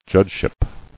[judge·ship]